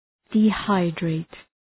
Προφορά
{di:’haıdreıt} (Ρήμα) ● αφυδατώνω